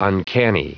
Prononciation du mot uncanny en anglais (fichier audio)
Prononciation du mot : uncanny